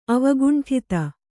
♪ avaguṇṭhita